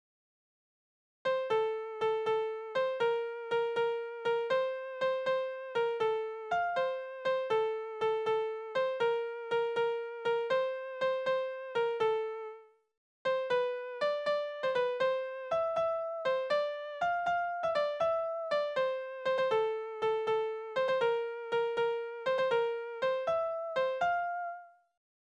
Tonart: F-Dur
Taktart: 6/8
Tonumfang: kleine Sexte
Besetzung: vokal